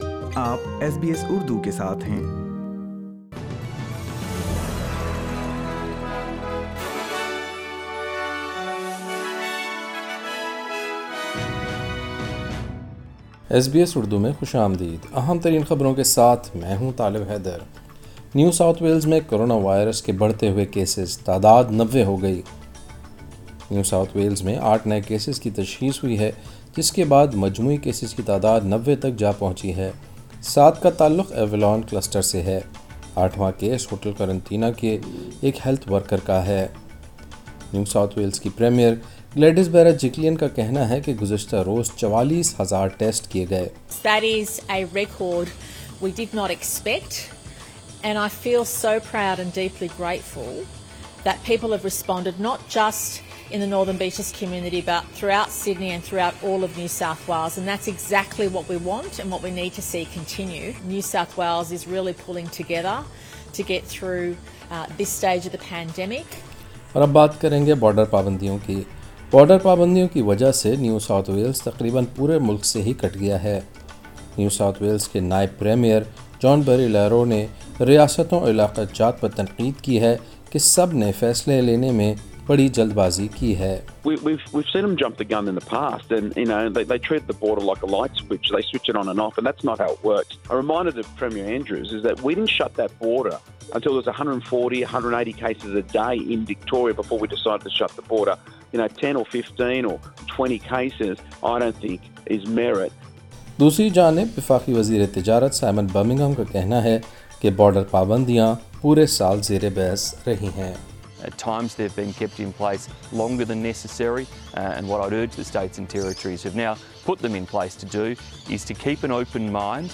ایس بی ایس اردو خبریں 22 دسمبر 2020